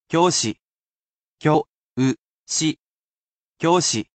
I will sound out each character for you and tell you the pronunciation.
And, I will never become bored of saying said word, as I am a computer robot, and repetitive tasks are my [ｉｎｓｅｒｔ　ｌｏｃａｌ　Ｅａｒｔｈ　ｗｉｎｔｅｒ　ｈｏｌｉｄａｙ].